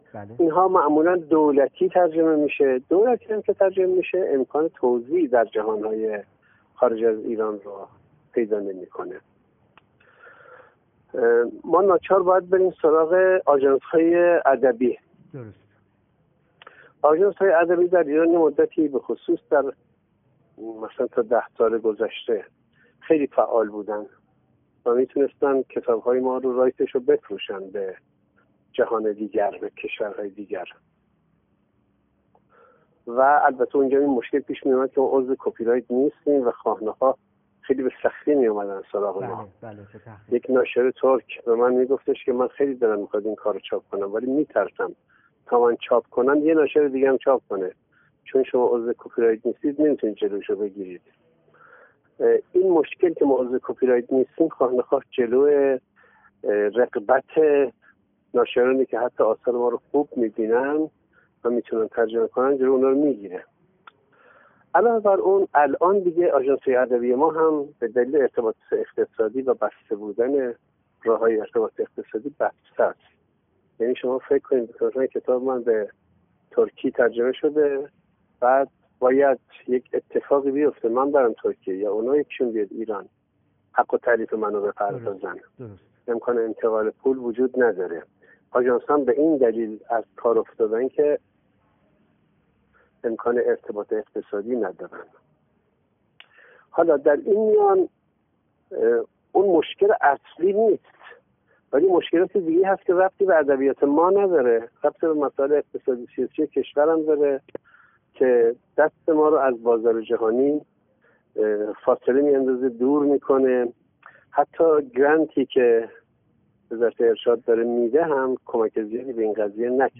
مصطفی رحماندوست در گفت‌وگو با ایکنا: